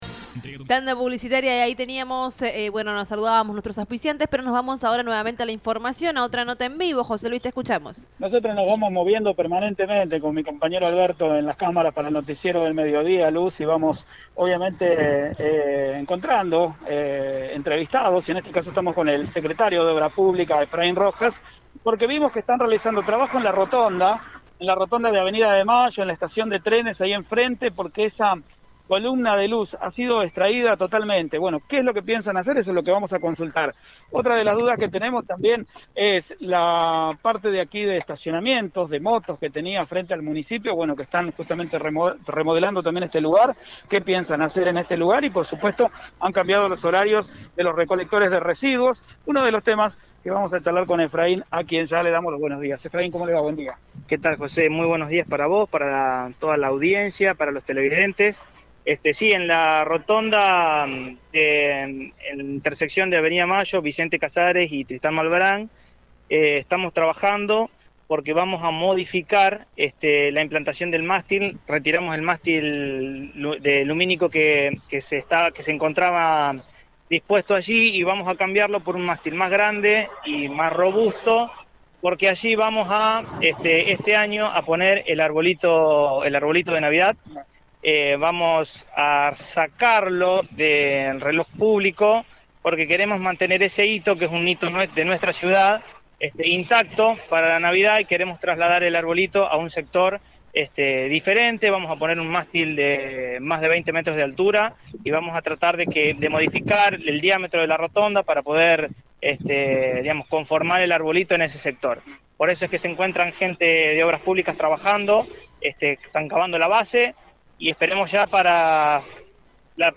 En Radio Eme entrevistamos al Secretario de Obra Pública, Efraín Rojas para conocer sobre los trabajos que realizan en la Rotonda central que está ubicada entre Av. de Mayo, T Malbran y Vicente Casares.